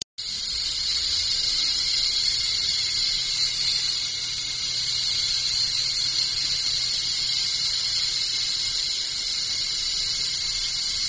Звук льющейся воды из душевой лейки удаленно